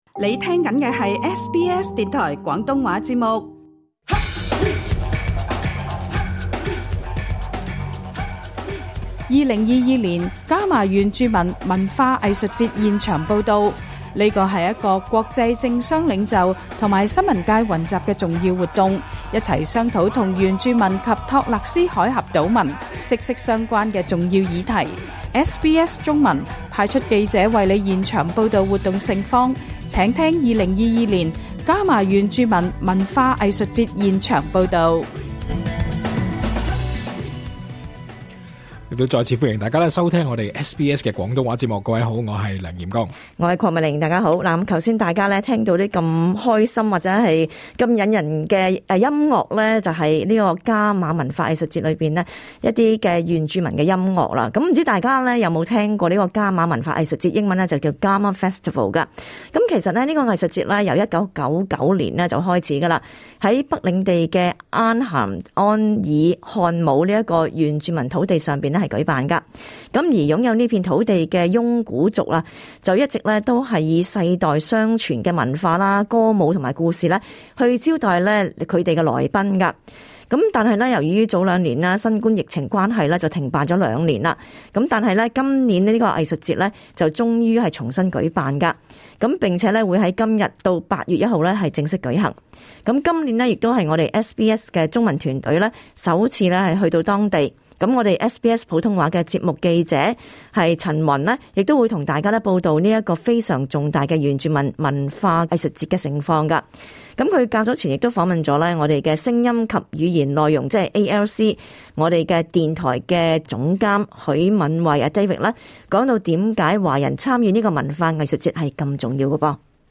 【原住民文化盛事】SBS中文直擊報道伽馬文化藝術節